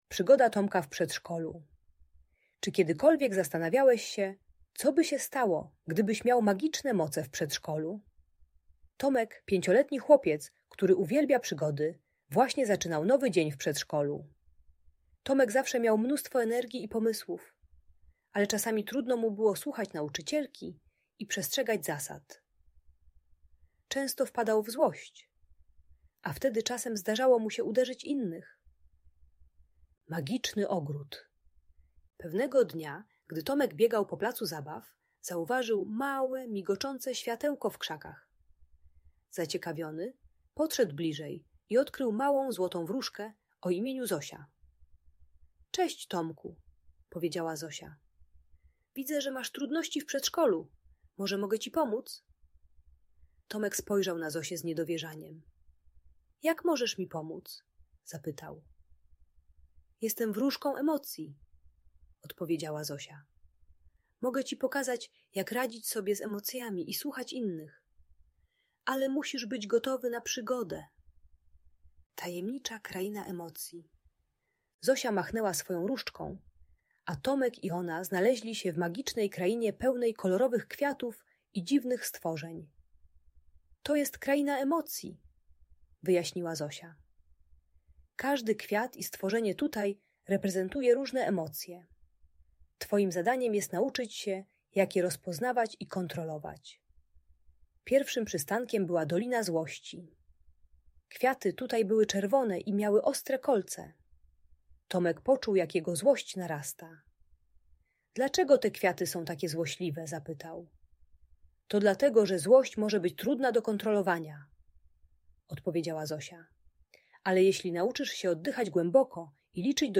Przygoda Tomka - Bunt i wybuchy złości | Audiobajka